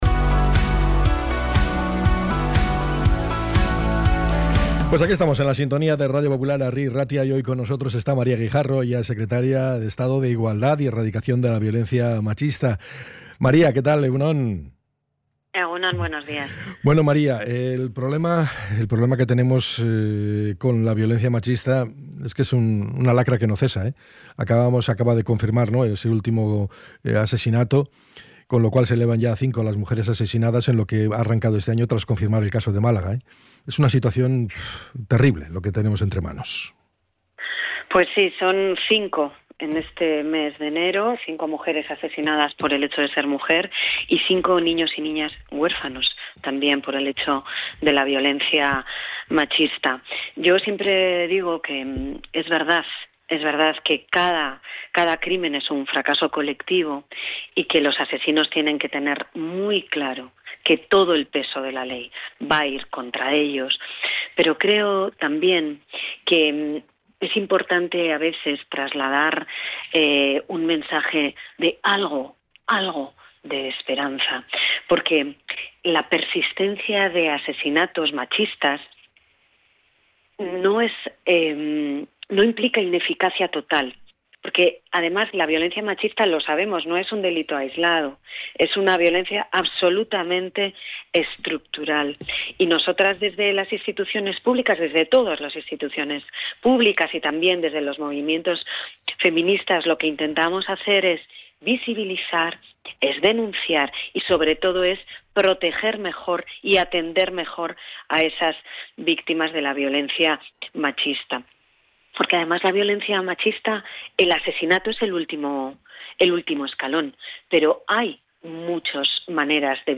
ENTREV.-SECRETARIA-ESTADO-IGUALDAD.mp3